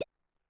New grunk collection SFX